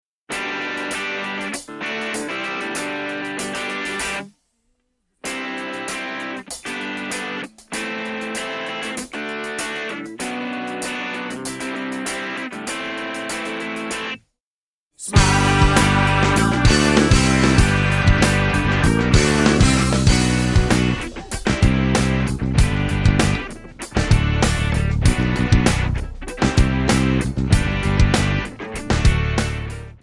Backing track Karaoke
Pop, Rock, 2000s